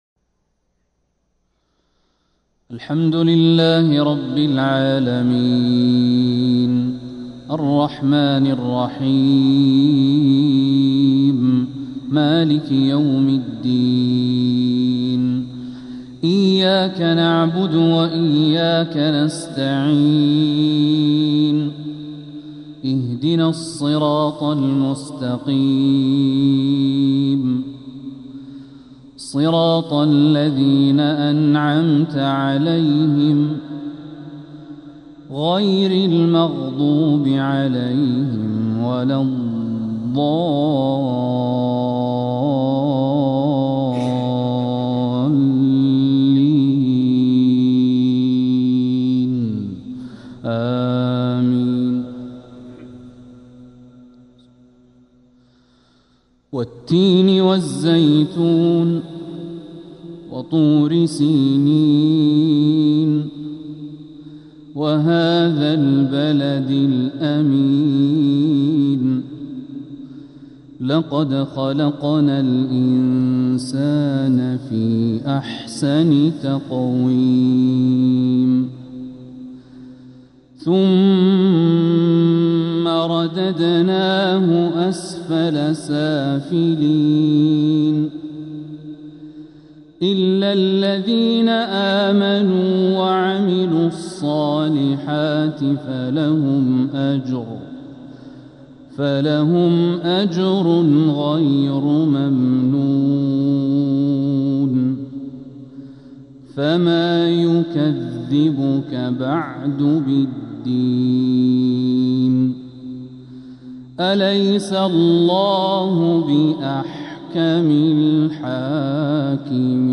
مغرب الجمعة 13 ربيع الأول 1447هـ سورتي التين و القدر | Maghrib prayer from Surah At-Tin and Al-Qadr 5-9-2025 > 1447 🕋 > الفروض - تلاوات الحرمين